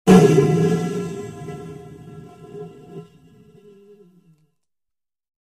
Звуки воздуха
Поток воздуха вырывается из трубы металлической